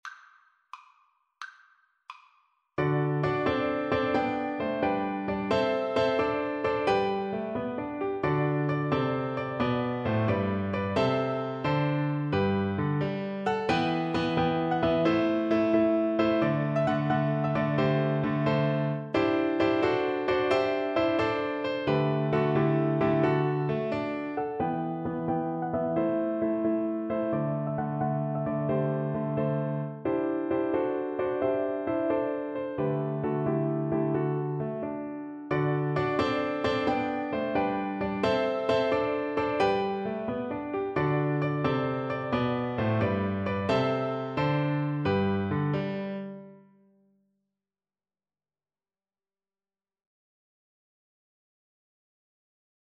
6/8 (View more 6/8 Music)
Traditional (View more Traditional Flute Music)